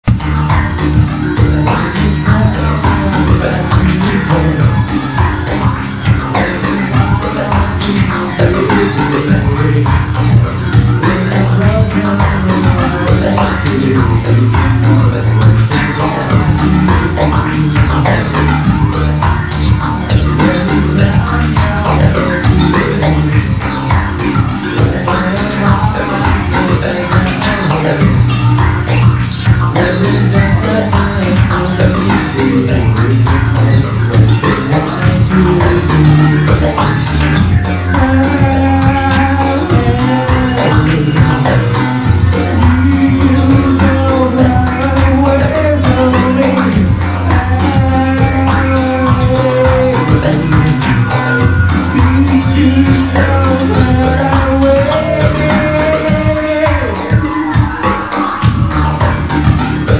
(MP3の音質は非常に悪いです。すみませんです。)